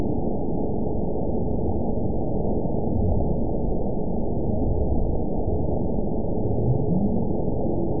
event 917362 date 03/29/23 time 01:15:27 GMT (2 years, 1 month ago) score 9.30 location TSS-AB01 detected by nrw target species NRW annotations +NRW Spectrogram: Frequency (kHz) vs. Time (s) audio not available .wav